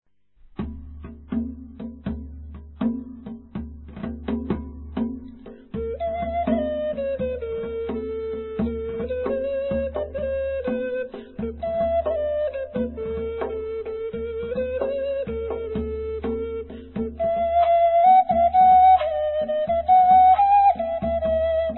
corne de flûte, coquilles, zarb